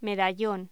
Locución: Medallón
voz